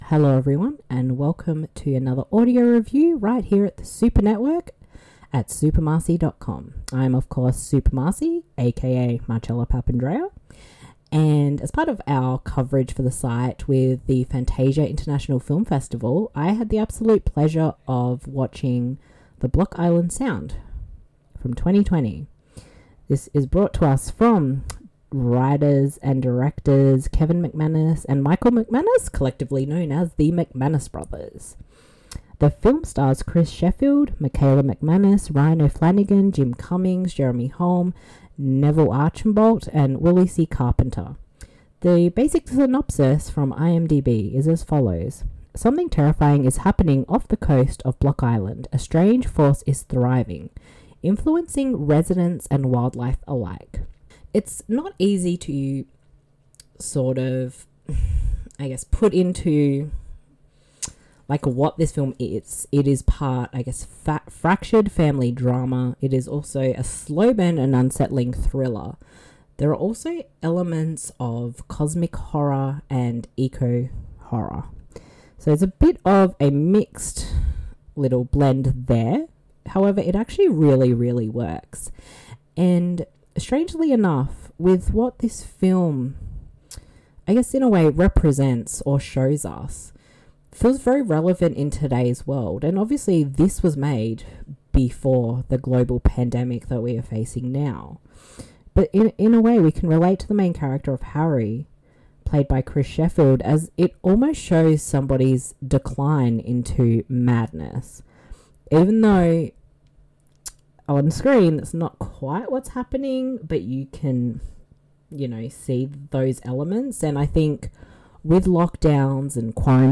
The following review of the film is in an audio format.
fantasia-2020-audio-review-the-block-island-sound.mp3